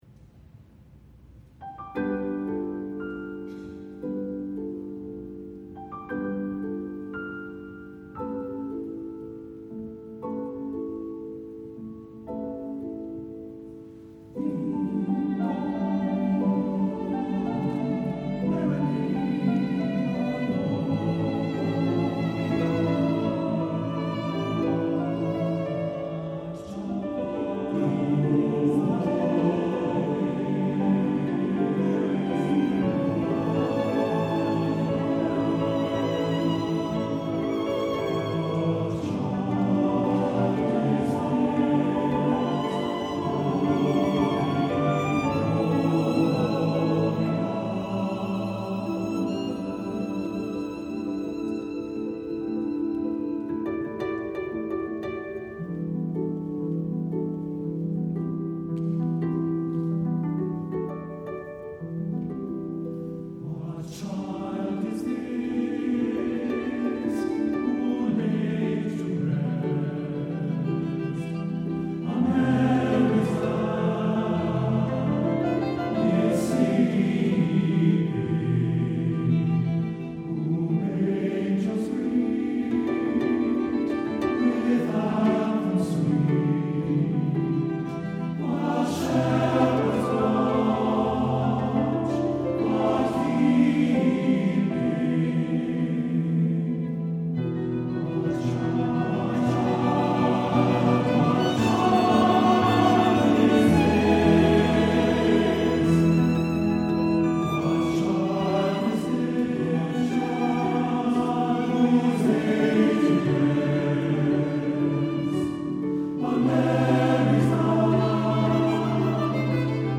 Style – Contemporary
Christmas Carols Difficulty